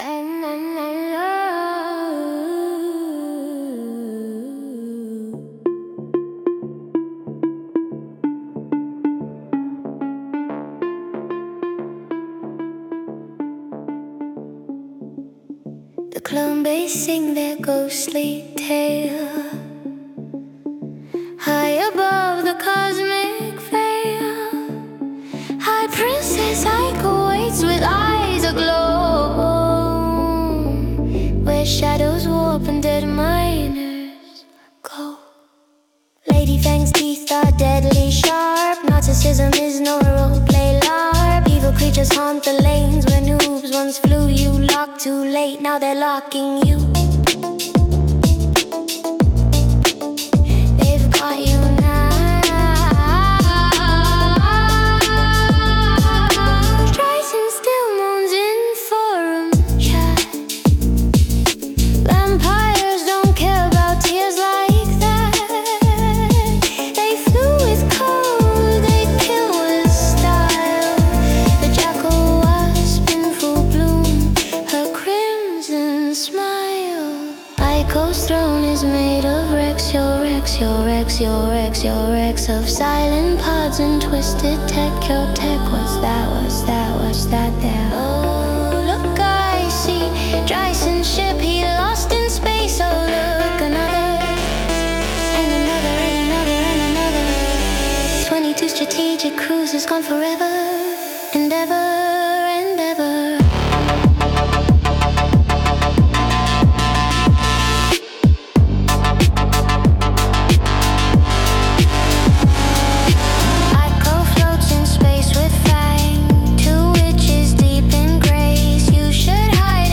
That AI song captured the 80;s pop alright.
Yep, it reminds me of those and that power rift on the guitar at 1:45 is just over the top.